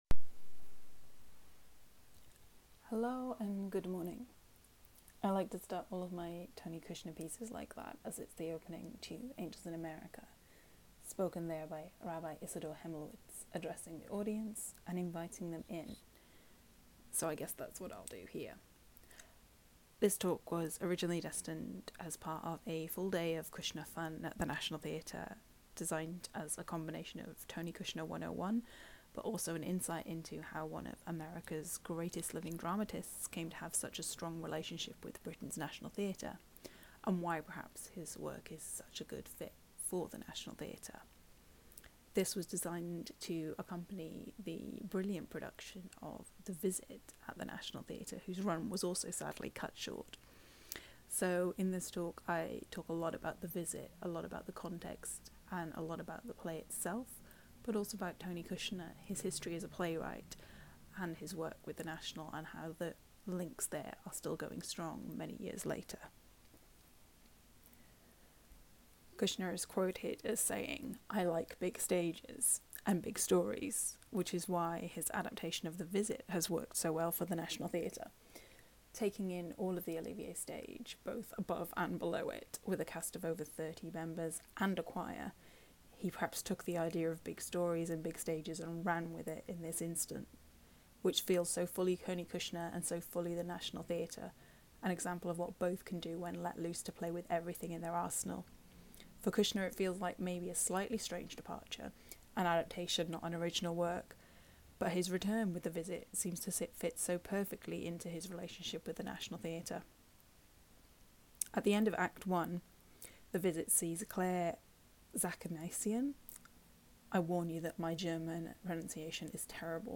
Audio recording of this talk is above, originally intended for an interactive discussion as part of the National Theatre’s learning programmes, this is a modified version of that talk in written and recorded form.